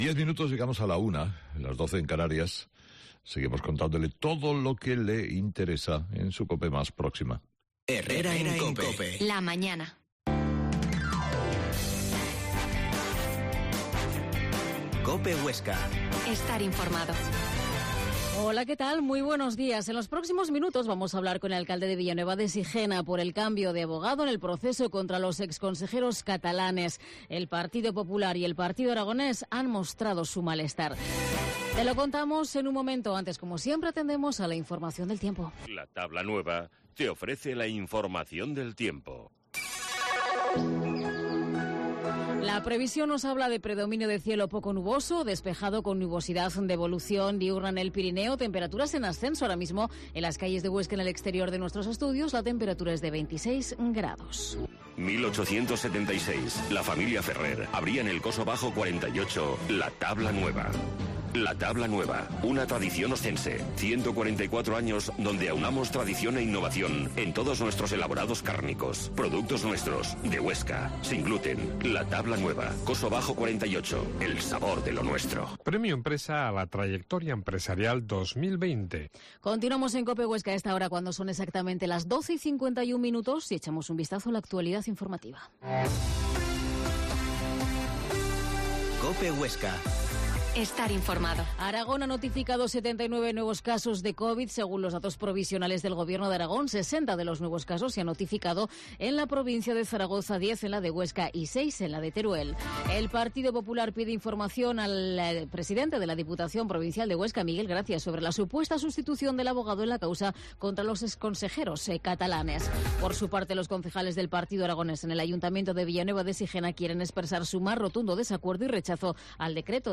Herrera en COPE Huesca 12.50h Entrevista al alcalde de Villanueva de Sijena, José Jaime Catellón